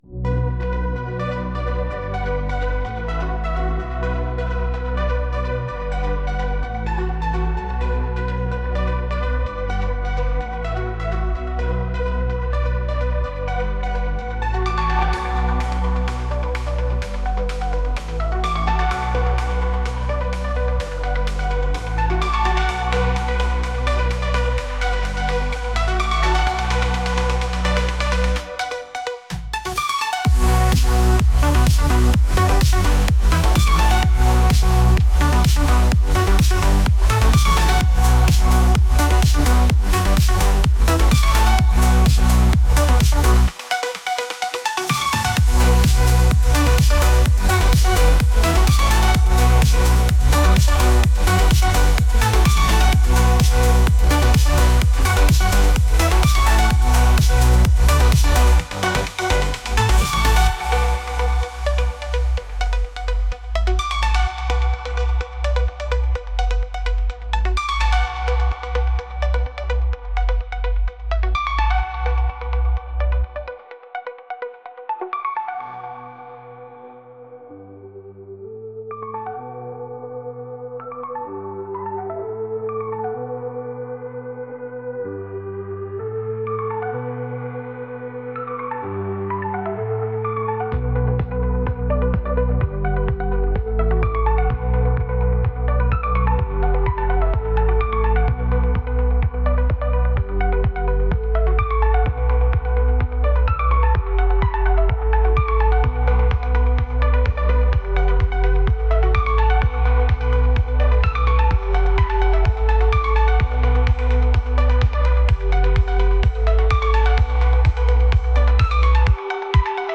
Synthwave Drive Track